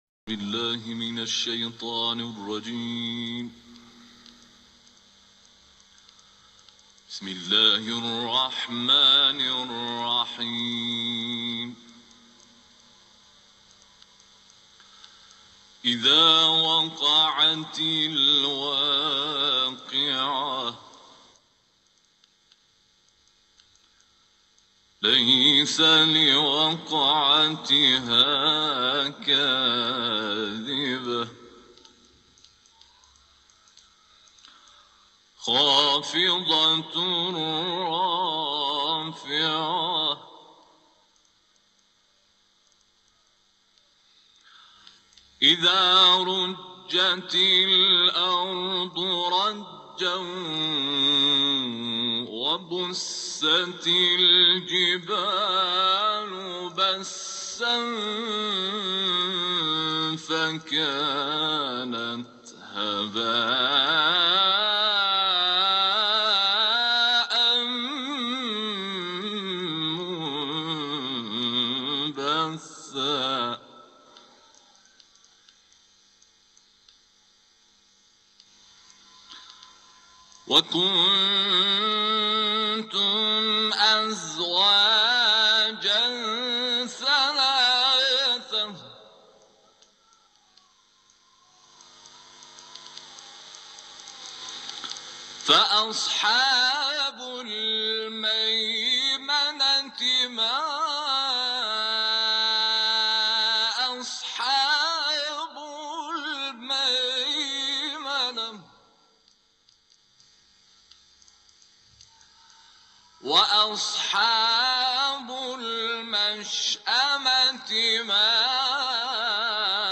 ، تلاوت ، قیامت